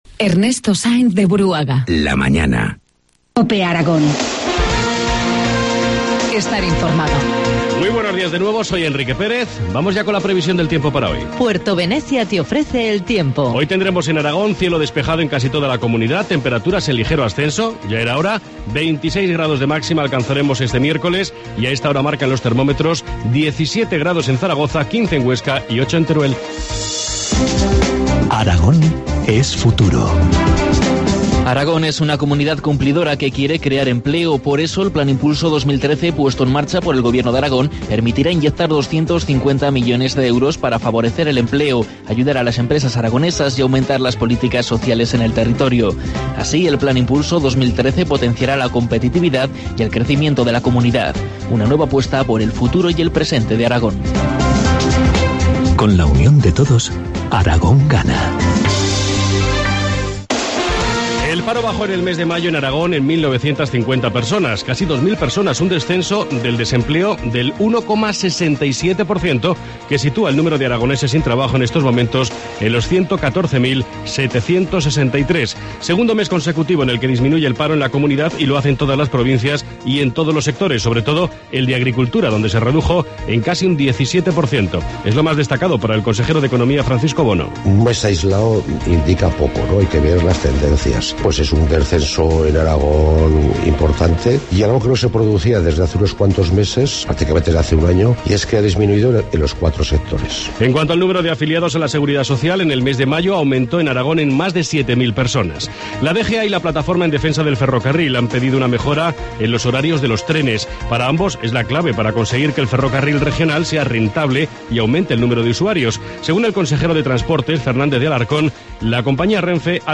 Informativo matinal, miercoles 5 junio 8,25 horas